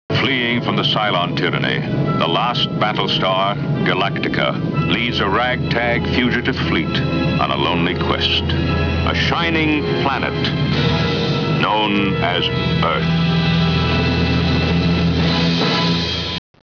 Listen to the classic closing remarks by Lorne Greene...in